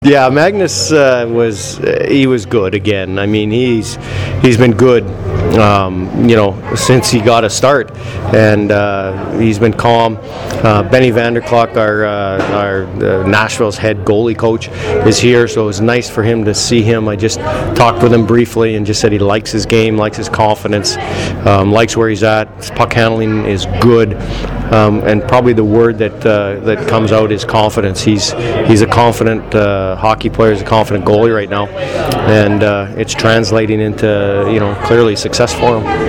Here is what everyone had to say following the Admirals 3-2 (OT) victory over the Chicago Wolves.